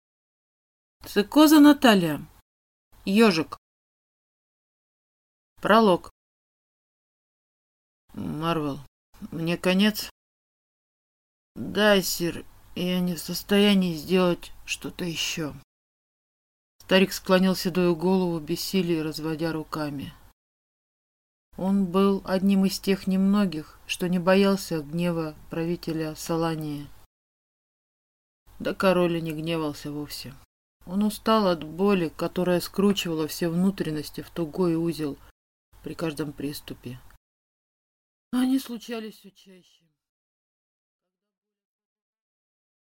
Аудиокнига Ёжик | Библиотека аудиокниг